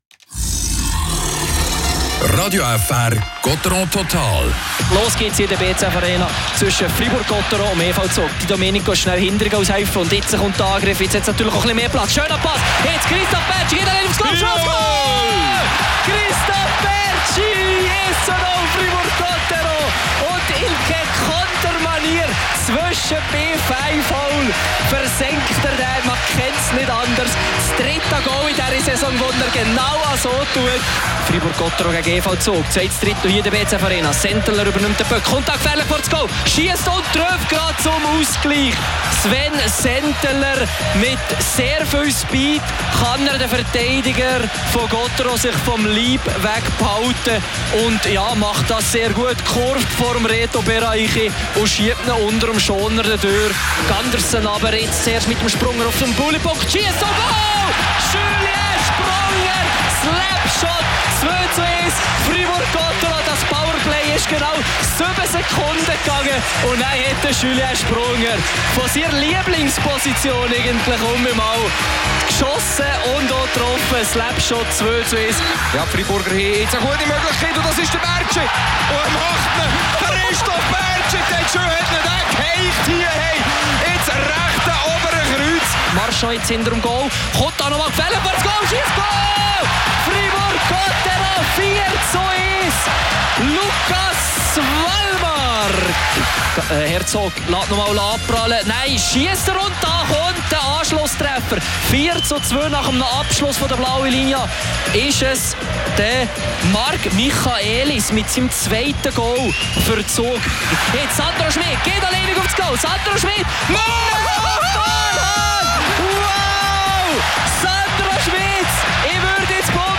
Die Drachen gewinnen zum sechsten Mal in der Saison. Heute schlagen sie die Innerschweizer mit 5-2. Interview mit dem Spieler